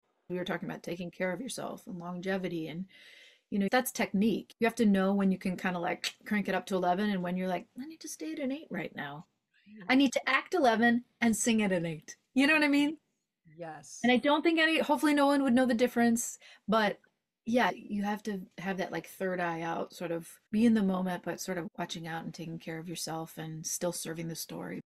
Musical theatre goddess Jessie Mueller tells us about vocal technique and longevity! Check out the full interview in the Journal of Singing and on the NATS YouTube channel.